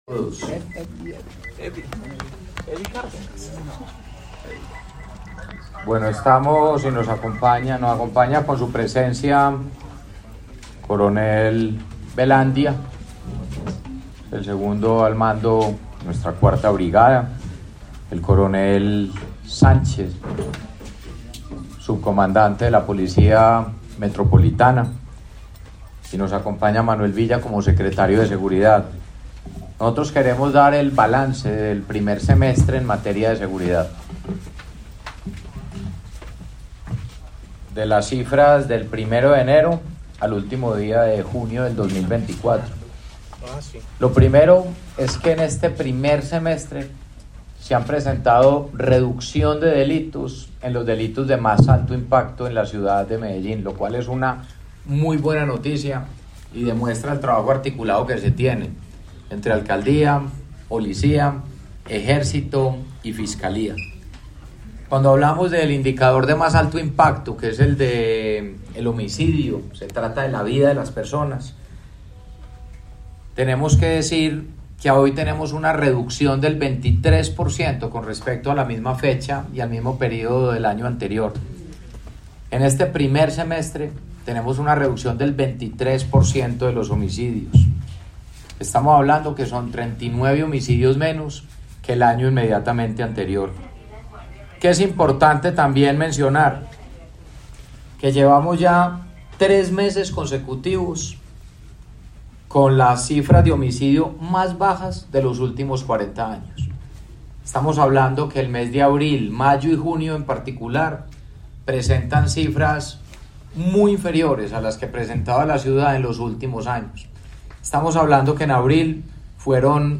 Palabras de alcalde Federico Gutiérrez Zuluaga, alcalde de Medellín